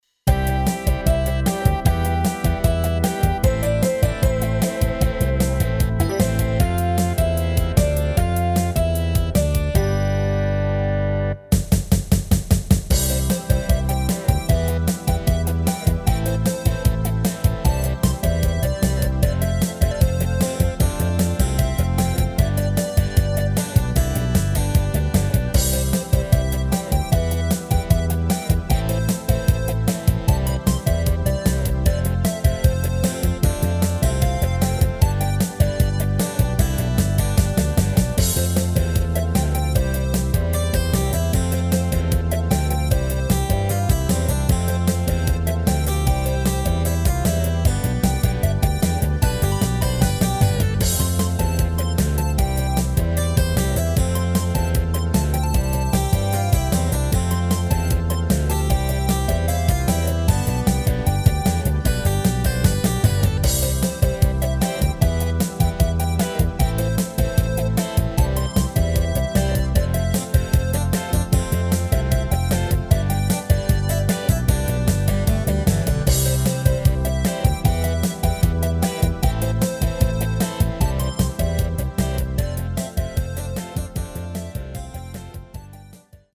Rubrika: Pop, rock, beat
Karaoke
HUDEBNÍ PODKLADY V AUDIO A VIDEO SOUBORECH